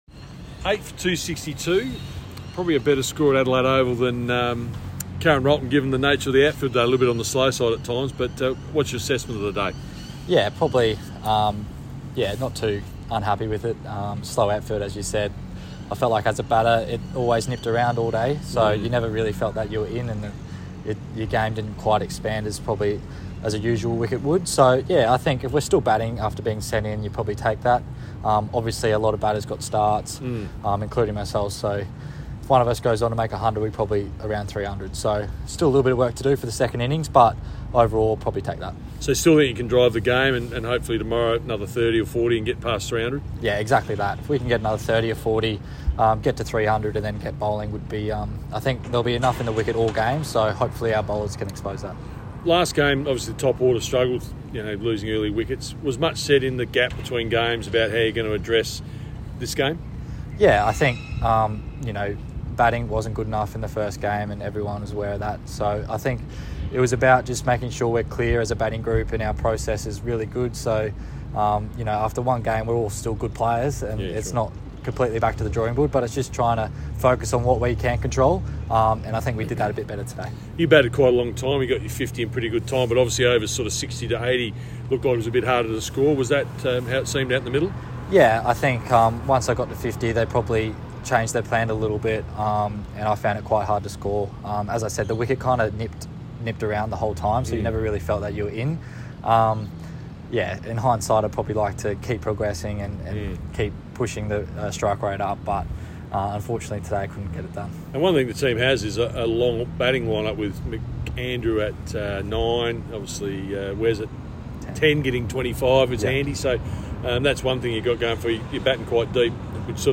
Nathan McSweeney post match interviewSouth Australia vs New South Wales, 5th Match, Sheffield Shield